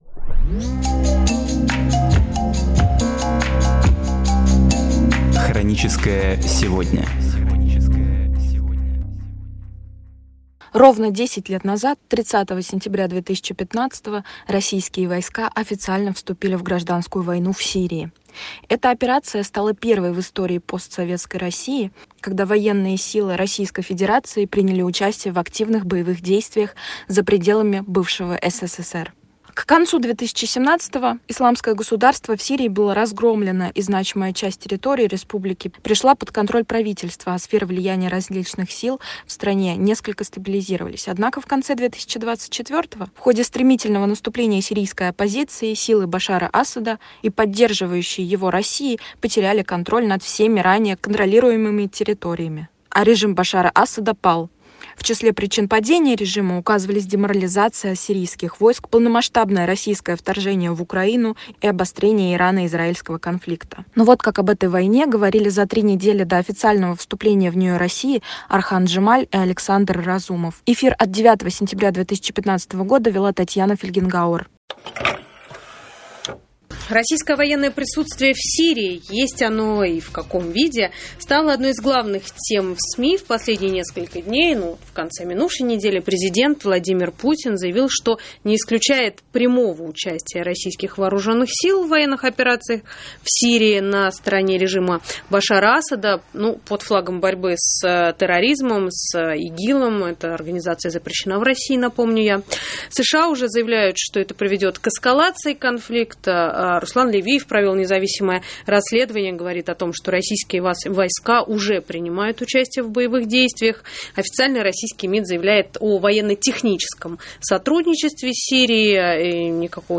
Программы из архива «Эха Москвы»